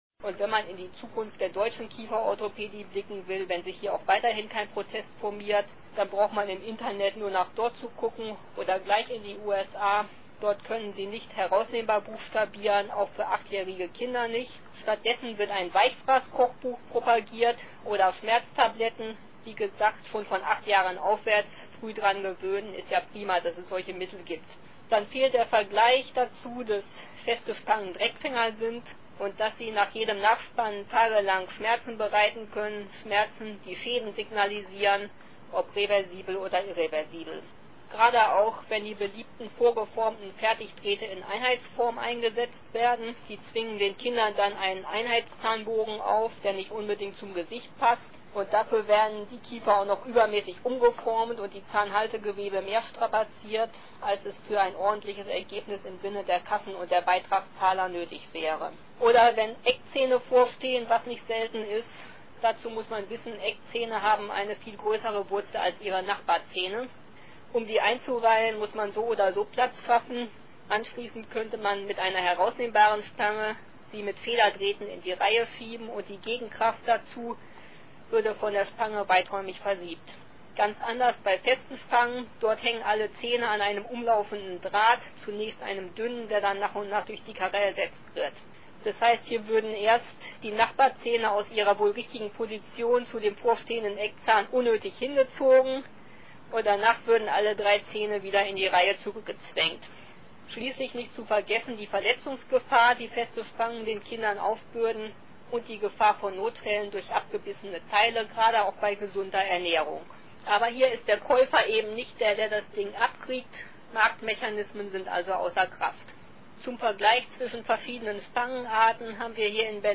Audio Kurzvortrag „Kieferorthopädie – hart oder sanft“, Schwerpunkt Kinder. Für Eltern, Gesundheitsberufe, ErzieherInnen usw.